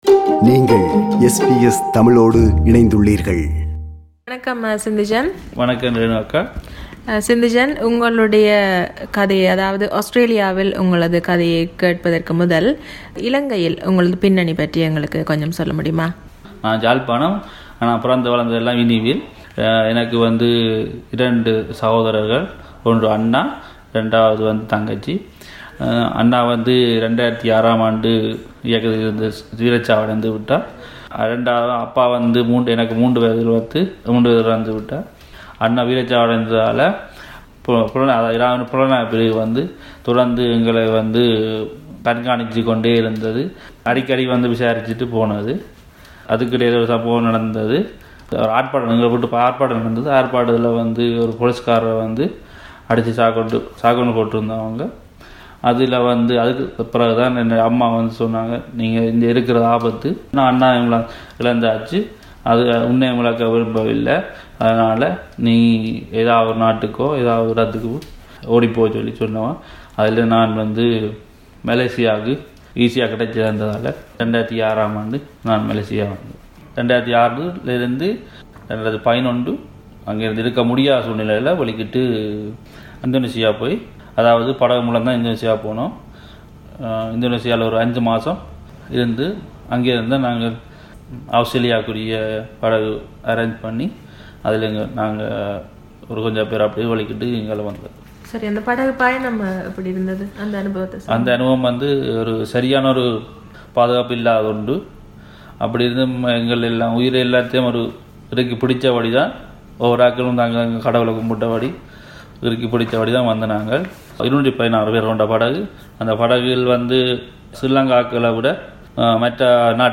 This is an interview with a failed asylum seeker.